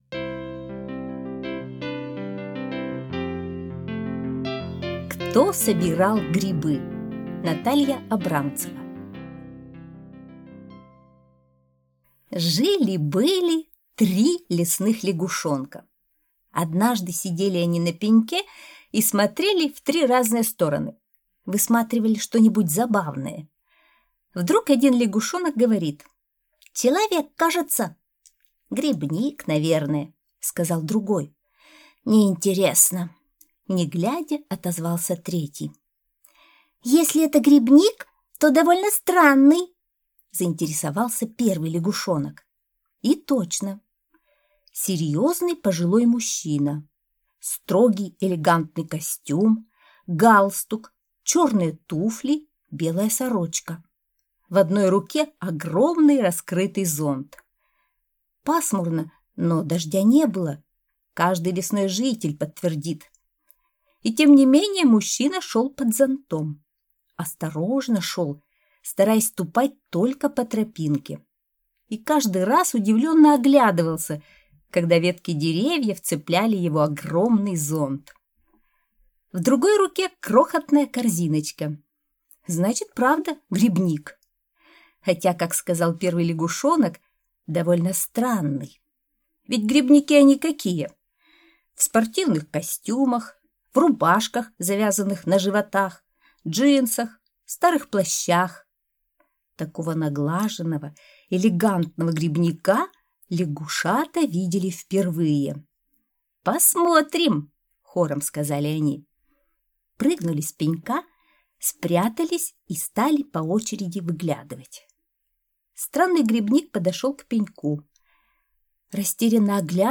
Кто собирал грибы - аудиосказка Натальи Абрамцевой - слушать скачать